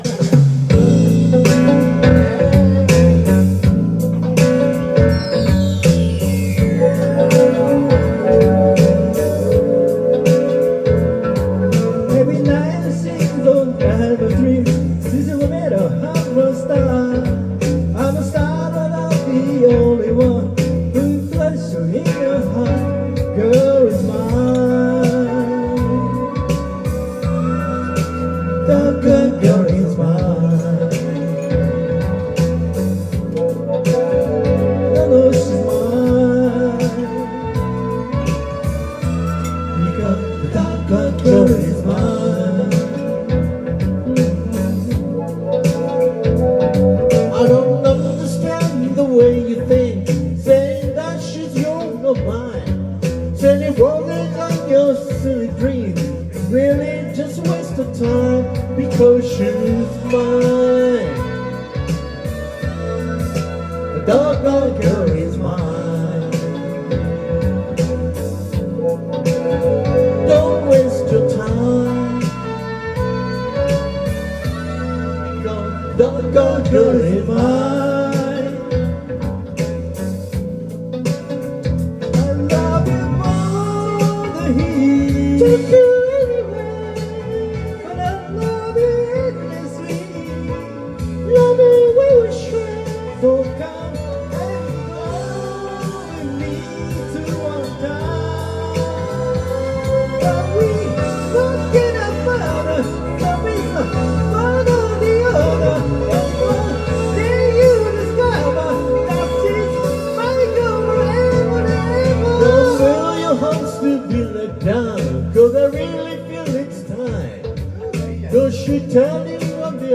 Duet & Chorus Night Vol. 19 TURN TABLE